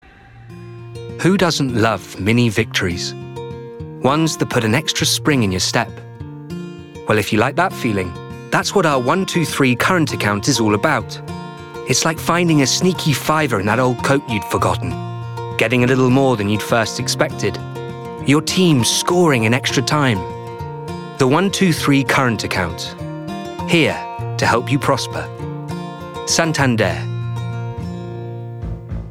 • Male
Showing: Commerical Clips
Soft, Reliable, Gentle, Clear